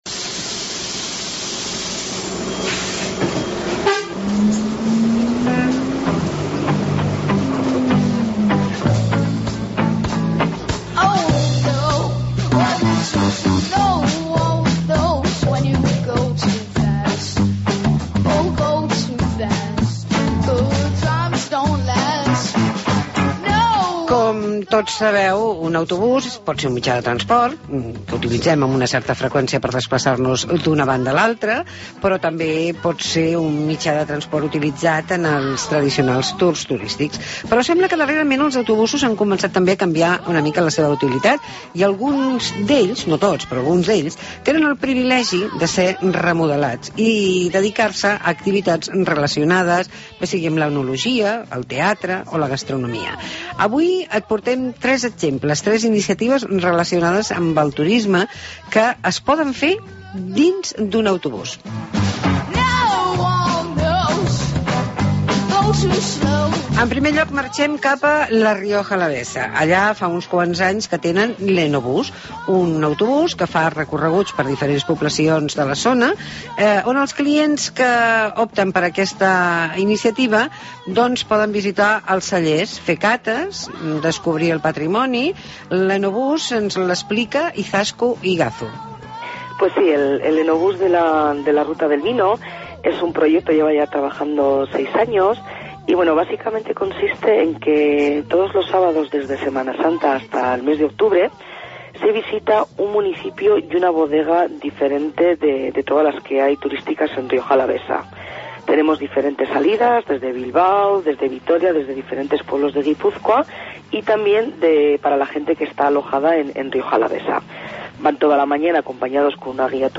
Reportaje Experiéncias sobre Ruedas